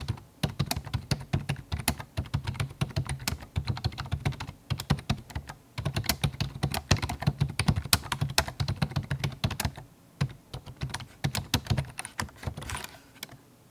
keyboard fast typing no accents
computer computer-keyboard fast key keyboard letters typing sound effect free sound royalty free Memes